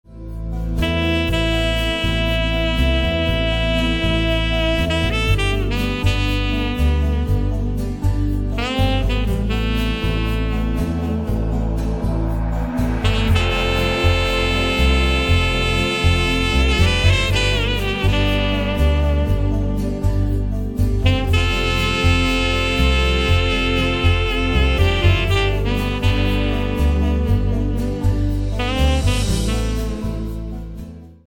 120 BPM
Sultry tenor with Spanish flavor
with a semi acoustic hypnotic groove